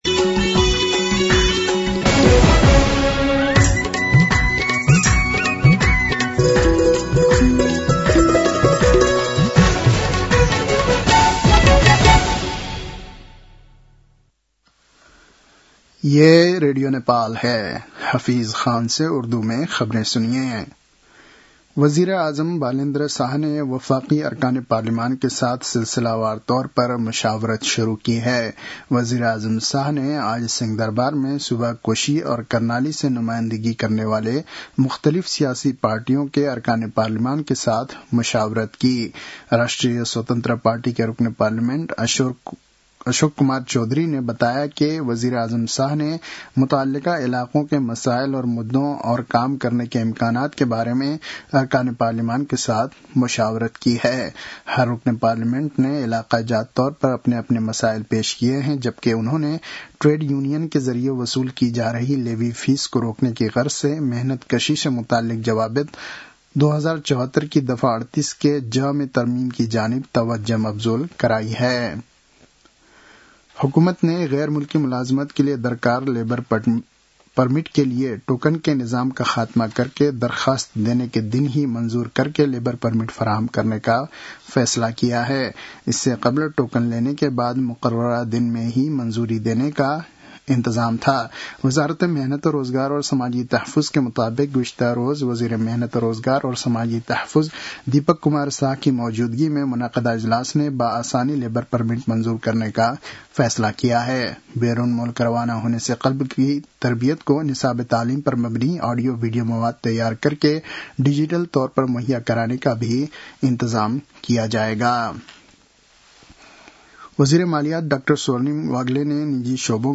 उर्दु भाषामा समाचार : १६ चैत , २०८२